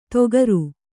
♪ togaru